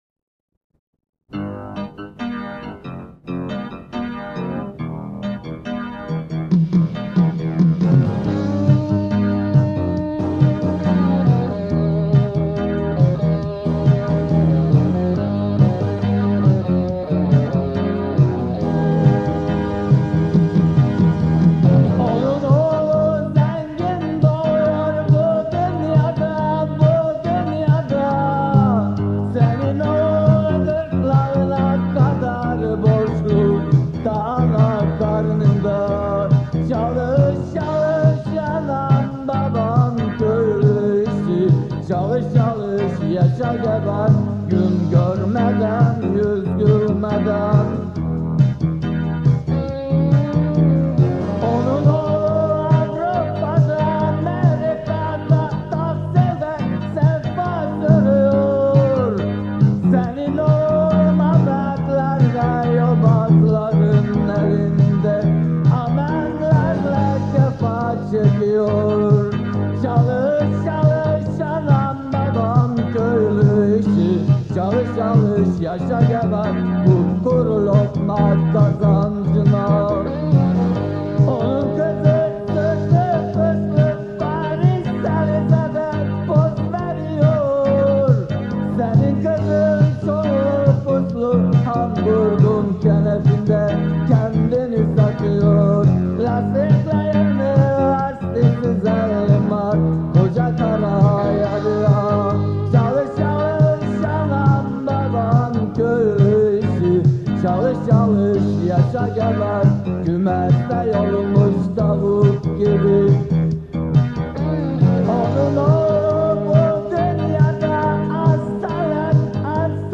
Pop-Rock-Şiirsel